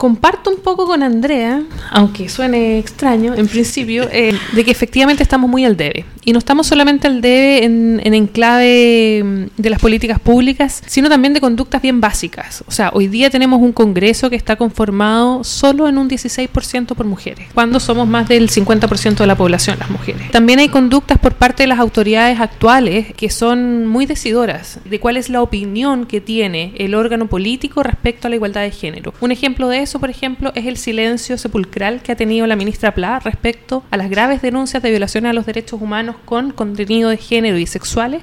El programa “Dialogo Constitucional” de nuestra emisora, se transmite todos los domingos, a las 11 y las 23 horas, contando con mesas de análisis y discusión de los distintos aspectos del proceso constituyente.